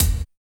87 GAT KIK-L.wav